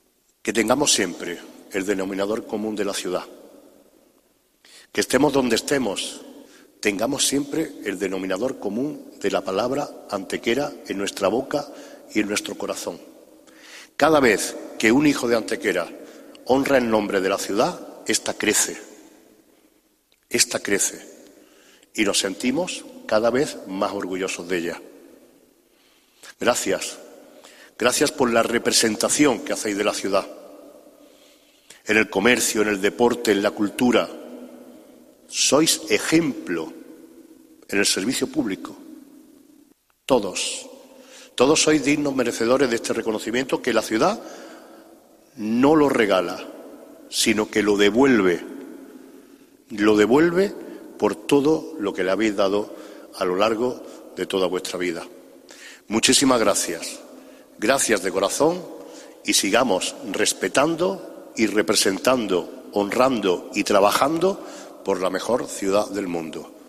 Tras las respectivas entregas de los galardones, tomó la palabra el Alcalde para cerrar el acto, agradeciendo y destacando la insigne labor que realizan todas las personas y empresas galardonadas en pro de su ciudad: "Hoy la madre está contenta.
Cortes de voz